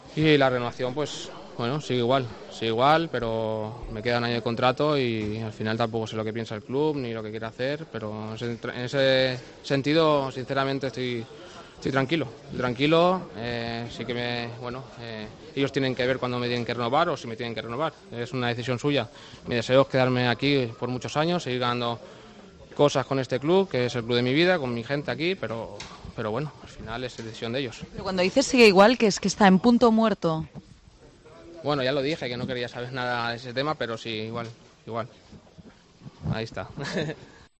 Jordi Alba declaró en la zona mixta del Camp Nou que su renovación con el Barcelona sigue en punto muerto.